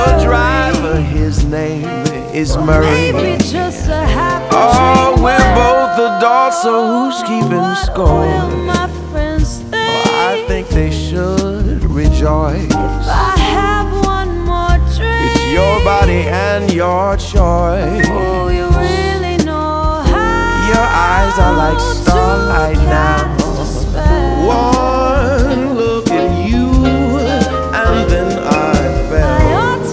Genre: Holiday